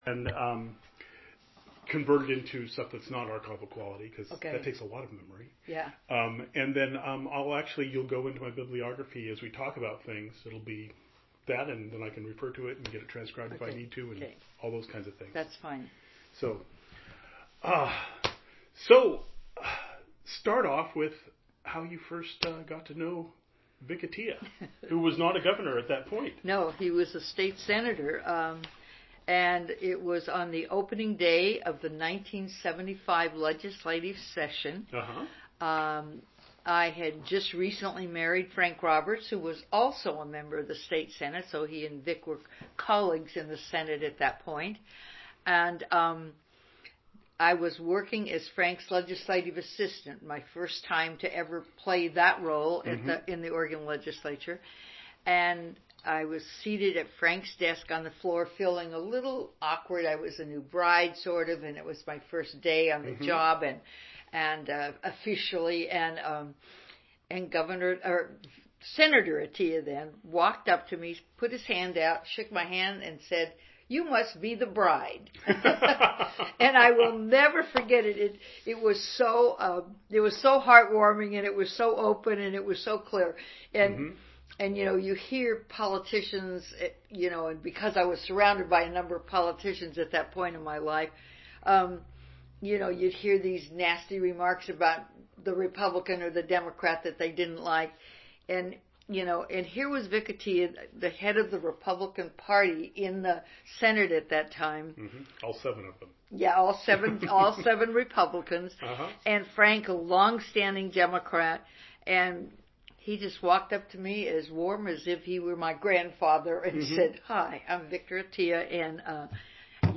38b5670e4271f32fefda0416ec1c5ae02ae609a1.mp3 Title Barbara Roberts interview on Atiyeh Description An interview of Oregon's former Democratic Governor Barbara Roberts (served 1991-1995) regarding her predecessor, Republican Governor Victor Atiyeh (served 1979-1987).